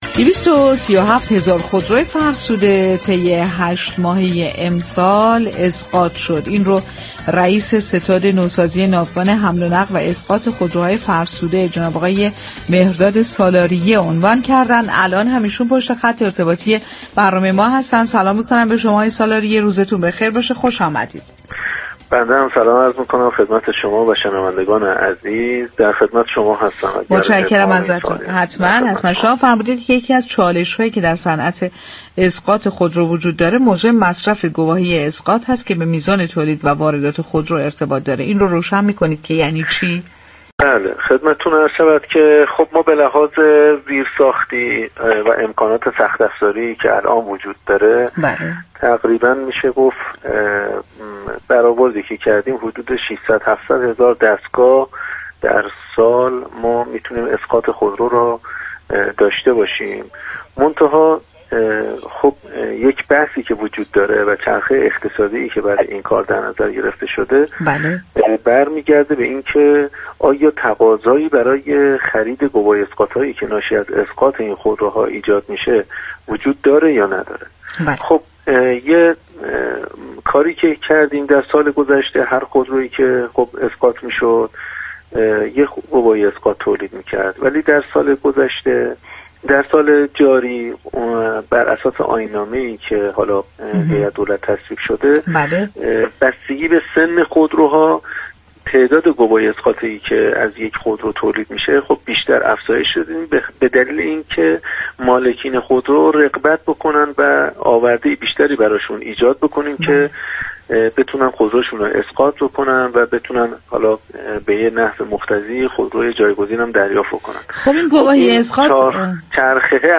به گزارش پایگاه اطلاع رسانی رادیو تهران، مهران سالاریه رئیس ستاد نوسازی ناوگان حمل و نقل و اسقاط خودروهای فرسوده در گفت و گو با «بازار تهران» اظهار داشت: بر اساس امكانات زیرساختی موجود، سالانه امكان اسقاط حدود 700 هزار دستگاه خودرو فرسوده را داریم.